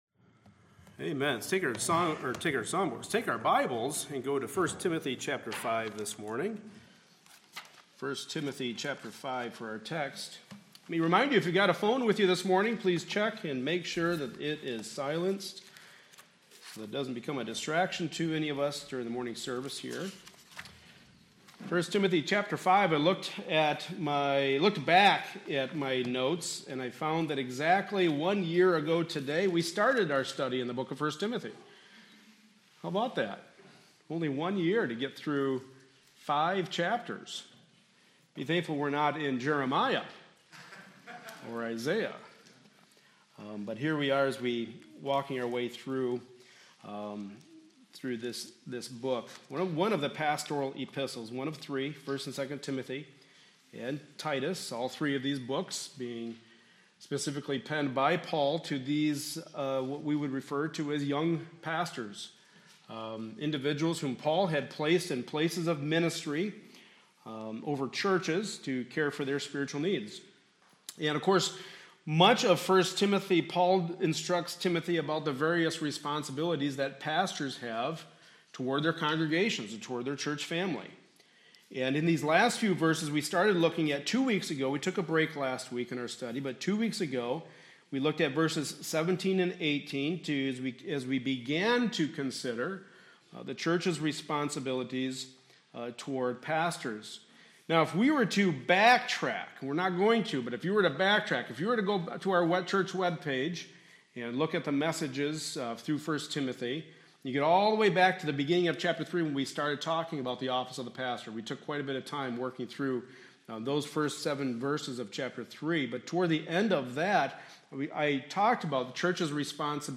Passage: 1 Timothy 5:19-25 Service Type: Sunday Morning Service